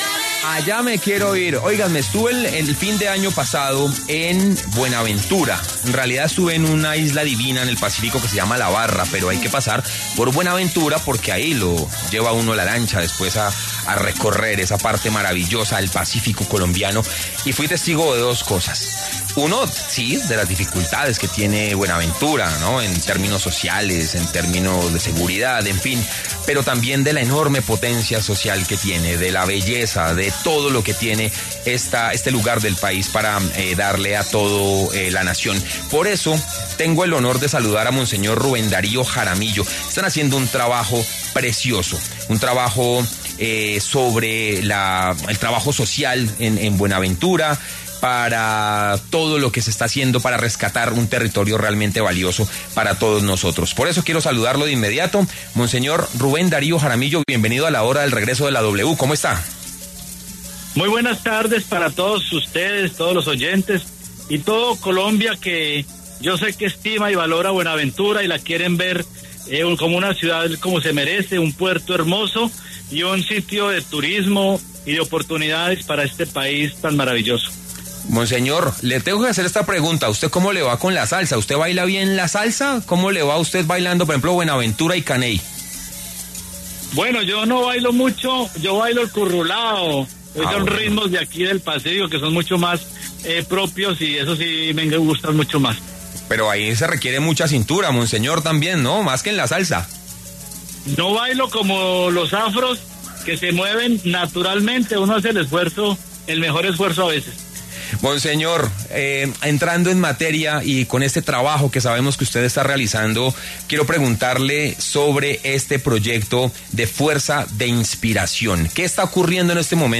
Monseñor Ruben Darío Jaramillo pasó por los micrófonos de La Hora del Regreso para compartir detalles sobre el proyecto de transformación y de visibilización llamado ‘Fuerza de Inspiración’ con el cual se busca destacar a la ciudad de Buenaventura en Valle del cauca.